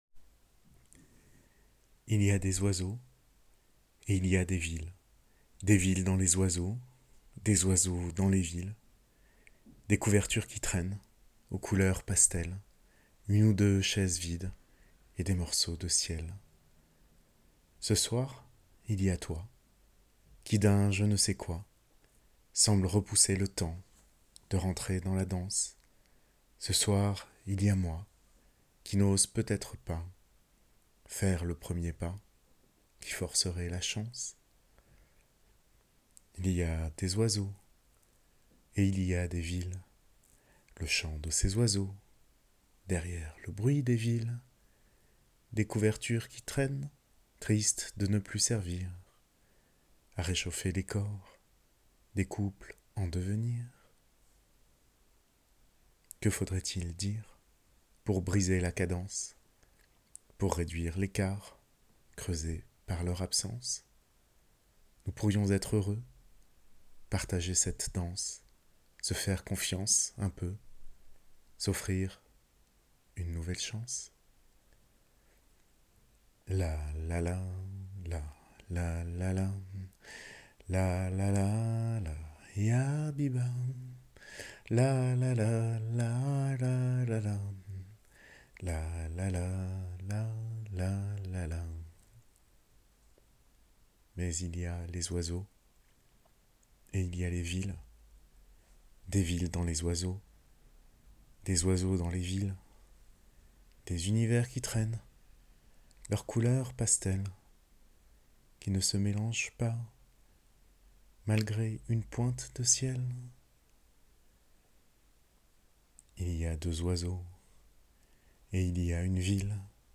Il y a des oiseaux (maquette)
dans Chansons